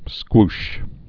(skwsh)